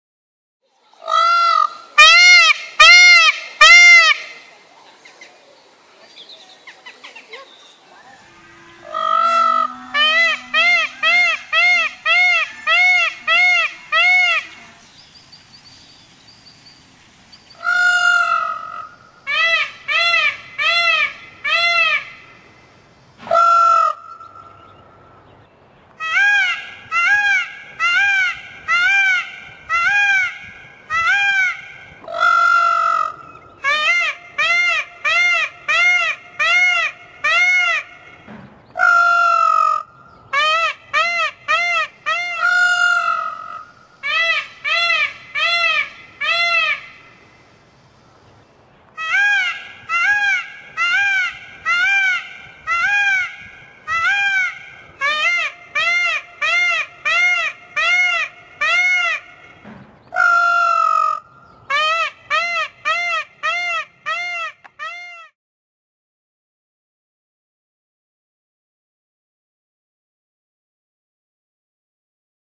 صدای حیوانات در جنگل: